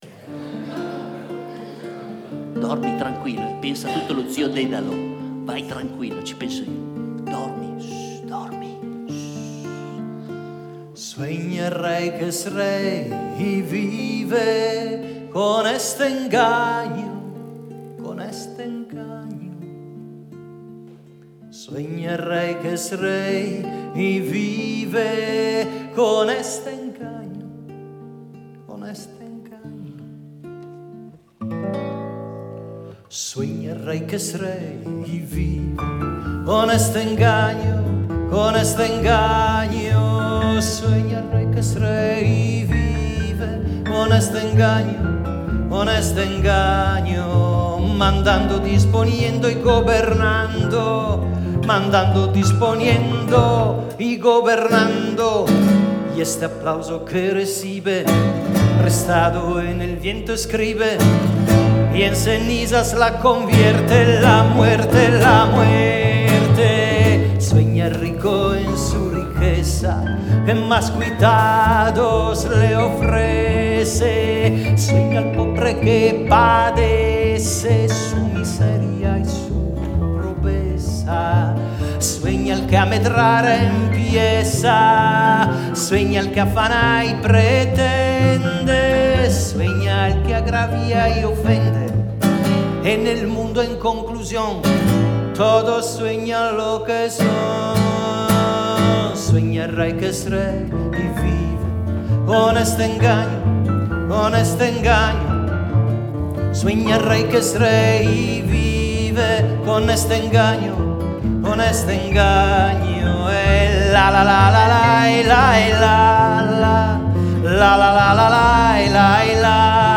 piano
chitarra
controbbasso.
Con queste canzoni inedite che potete ascoltare – e per i più dotati informaticamente scaricare anche senza la mia autorizzazione 😉 pubblico due brevi estratti dello spettacolo musicale “Icaro… vola basso!”